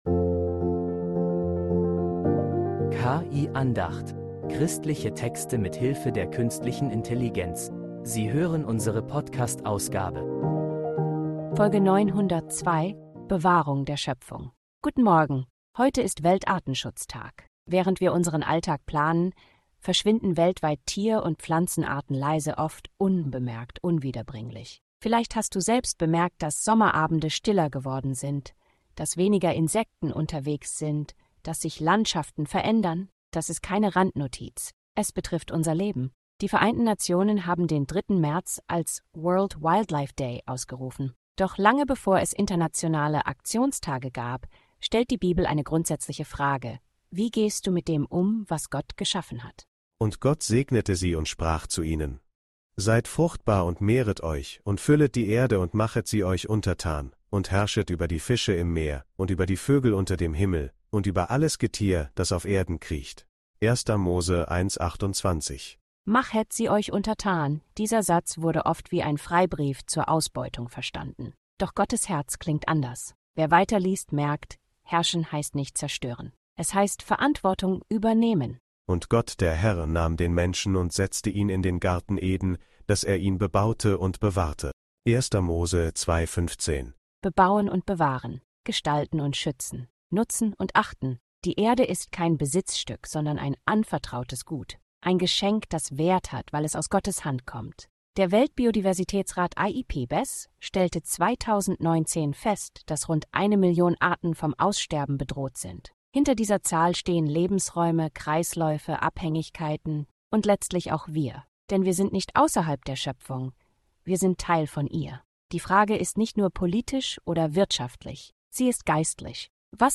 Eine Andacht zum Weltartenschutztag, die Hoffnung und Verantwortung verbindet